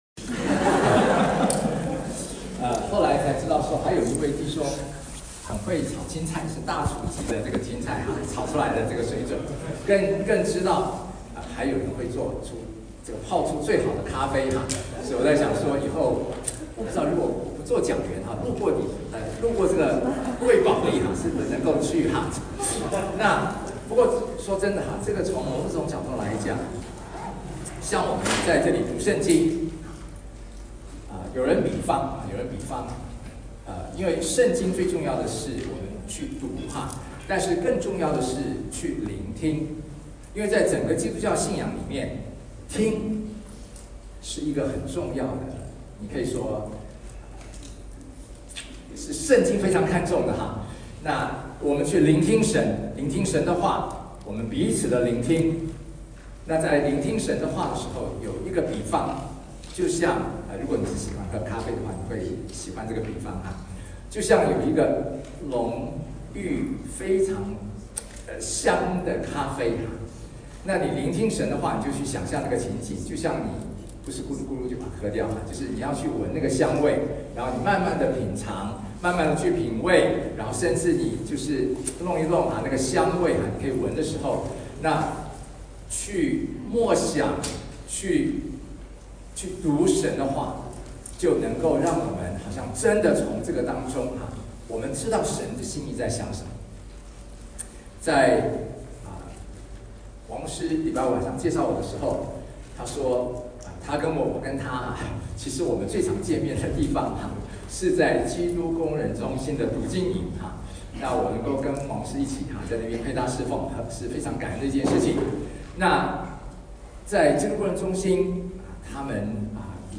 证道: 从圣约认识神对人的救恩之路 (二）：亚伯拉罕和摩西之约